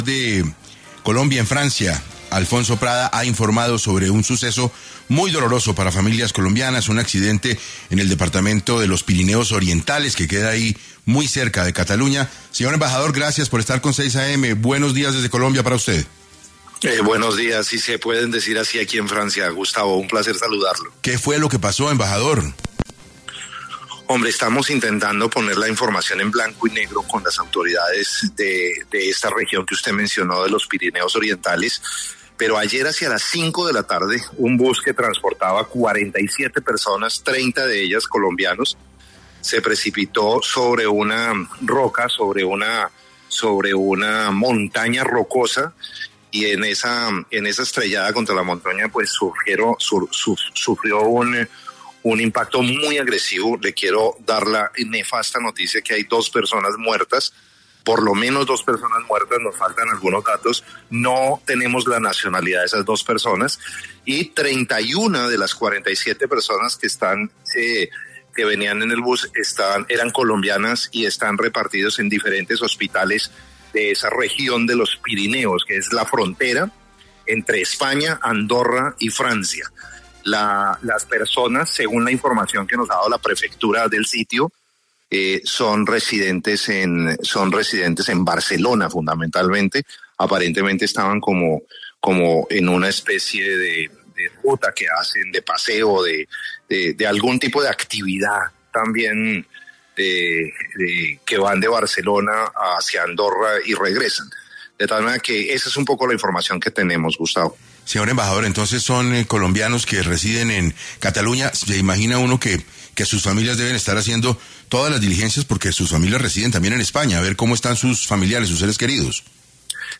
El embajador de Colombia en Francia, Alfonso Prada, aseguró en 6AM que aún no conocen la nacionalidad de todos los accidentados, y dijo que tienen abiertas las líneas de atención para los familiares de los colombianos heridos.